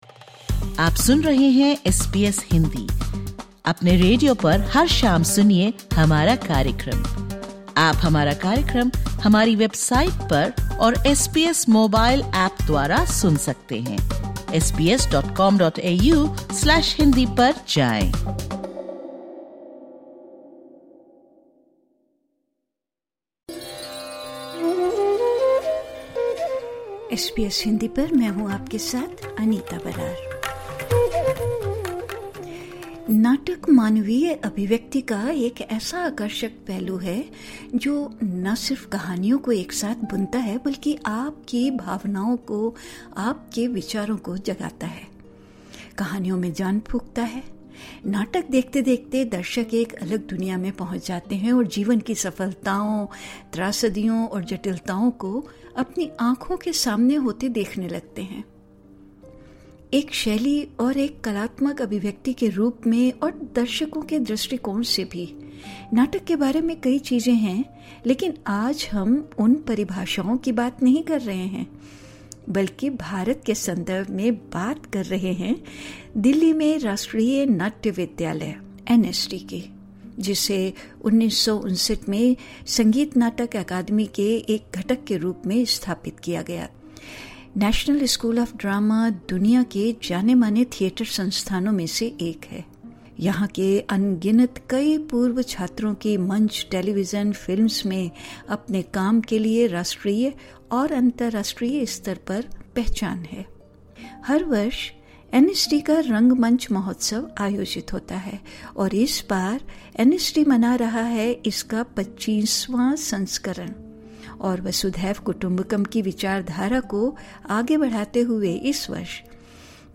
इस वर्ष भारत के राष्ट्रीय नाट्य विद्यालय ने अपने वार्षिक नाट्य समारोह ‘भारत रंग महोत्सव’ को अंतर्राष्ट्रीय भागीदारी के लिए ‘विश्व जन रंग’ के रूप में विस्तारित किया है। इस पॉडकास्ट में, एसबीएस हिंदी से बात करते हुए, एनएसडी के निदेशक चितरंजन त्रिपाठी ने इस भागीदारी की आवश्यकताओं और अन्य बातों पर चर्चा की।